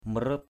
/mə-ru:p/ 1.